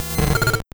Cri de Loupio dans Pokémon Or et Argent.